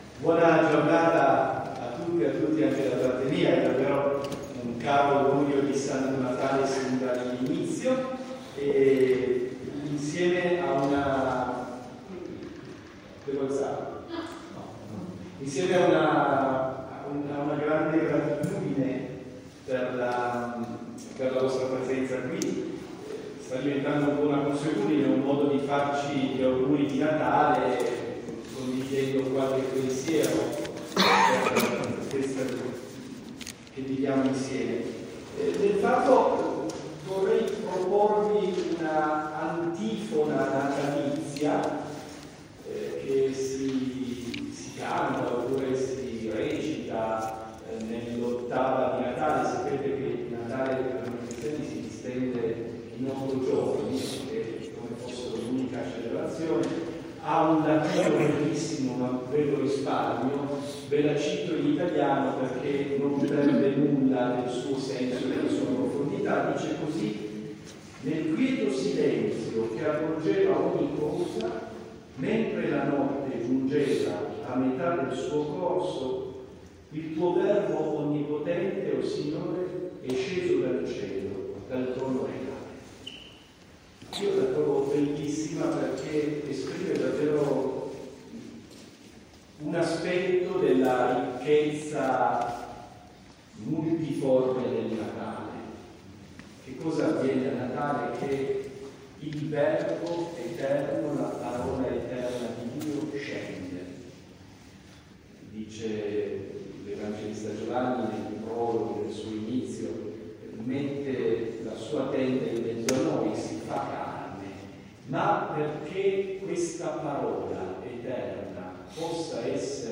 TORINO – Lunedì 23 dicembre 2024 il cardinale Roberto Repole, arcivescovo di Torino e vescovo di Susa, ha incontrato giornalisti e operatori dei mass media per il tradizionale augurio di Natale a loro e alla città.